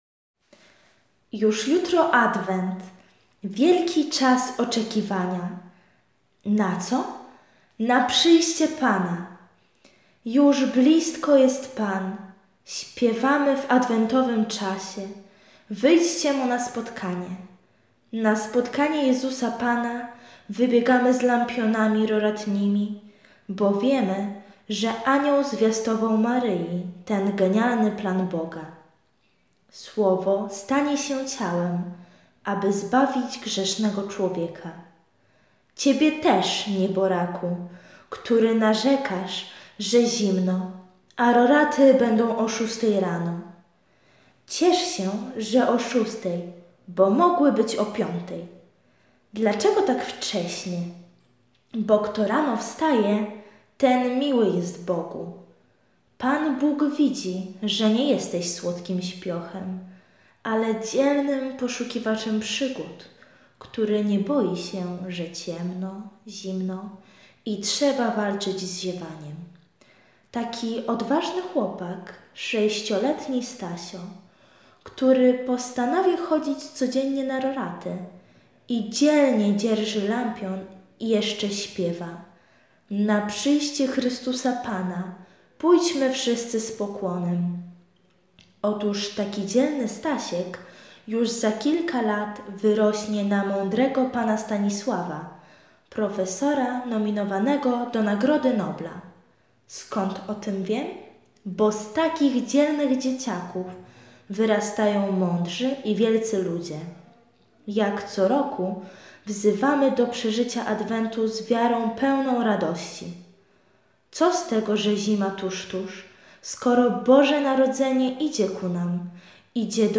Komentarz do Ewangelii z dnia 26 listopada 2016 czyta